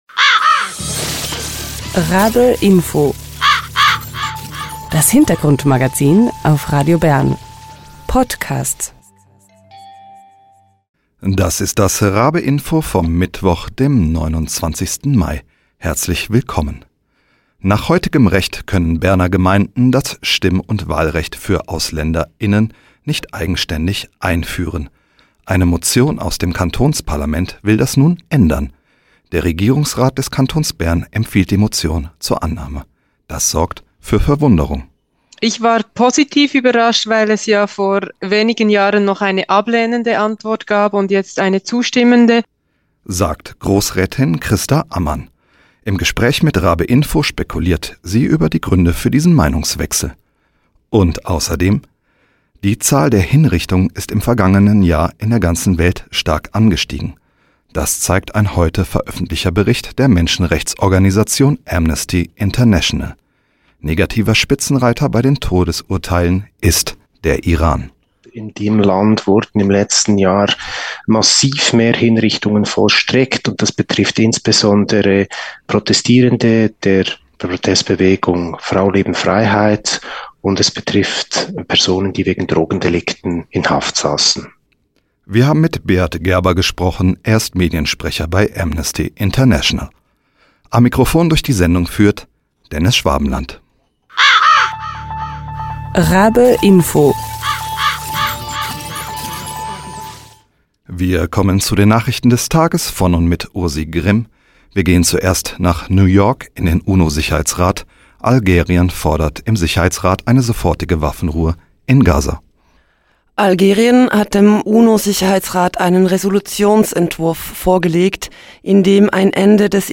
Wir sprechen mit Grossrätin Christa Ammann über die Motion.